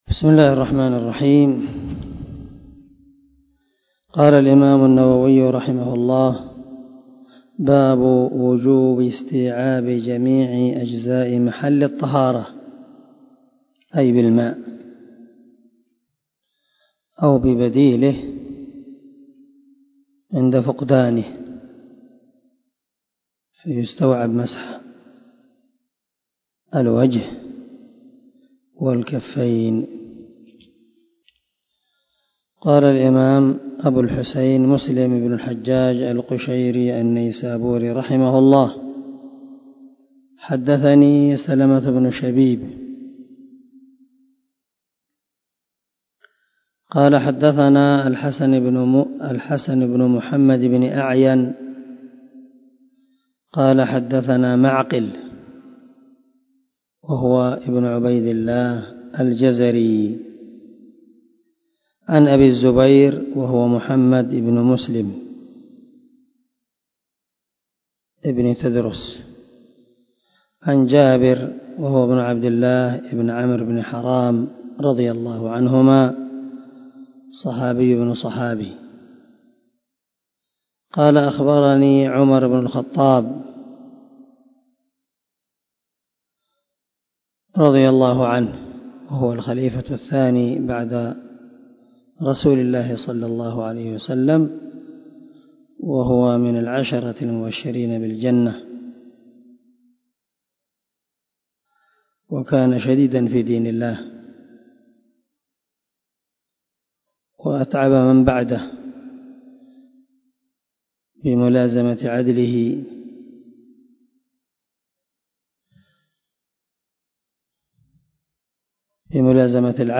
183الدرس 11 من شرح كتاب الطهارة حديث رقم ( 243 ) من صحيح مسلم